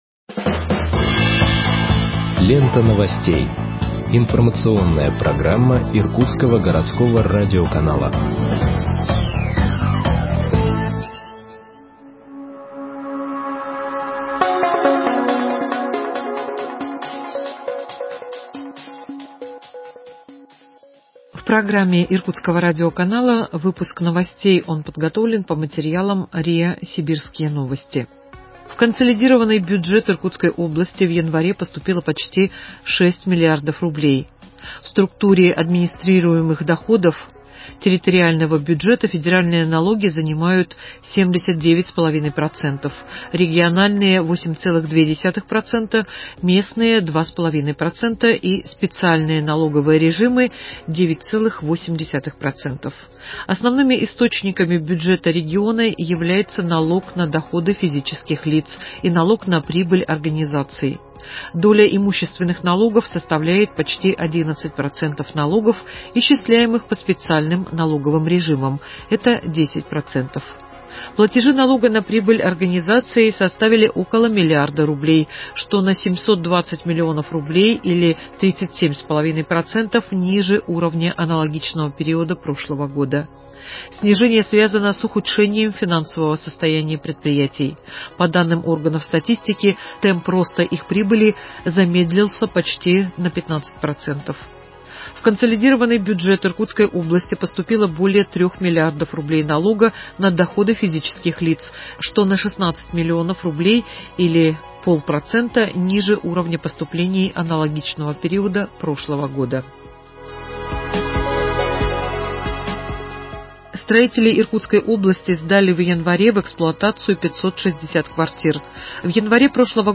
Выпуск новостей в подкастах газеты Иркутск от 09.03.2021 № 1